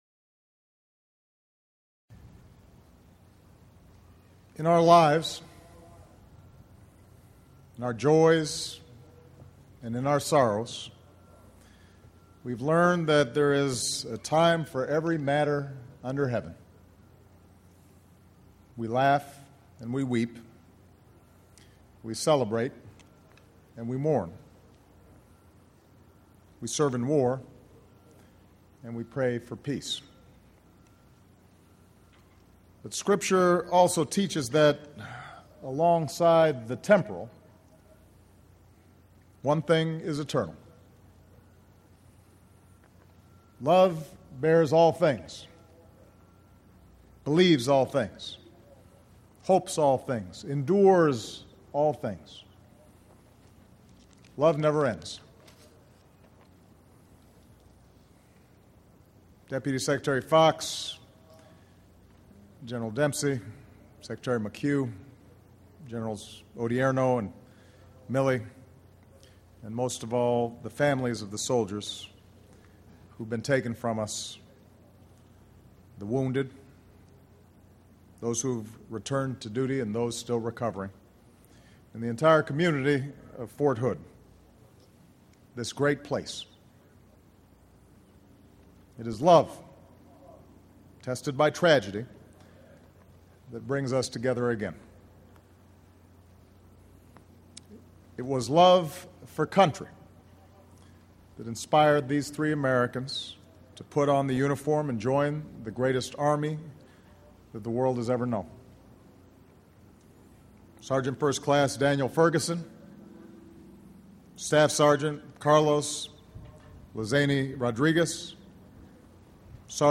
U.S. President Barack Obama speaks at a memorial service for servicemen killed in a shooting at the Fort Hood, Texas military base